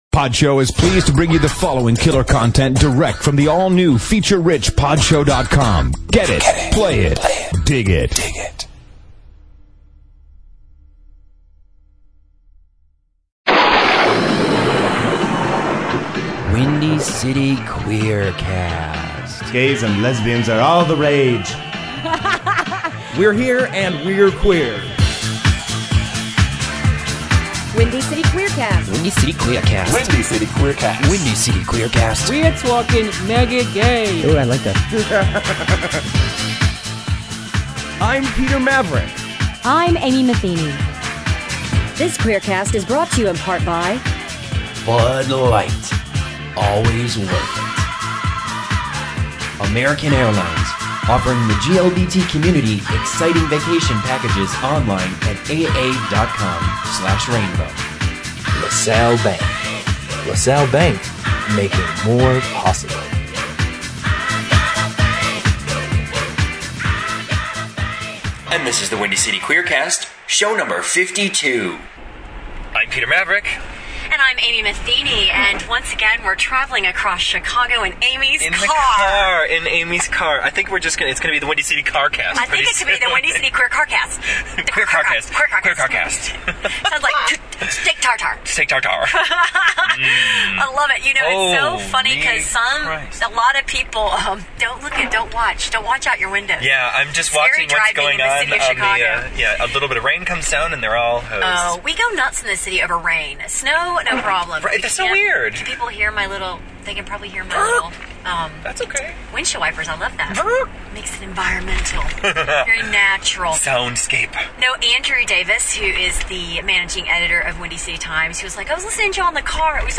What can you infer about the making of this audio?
We're in the car on the way to somewhere mysterious.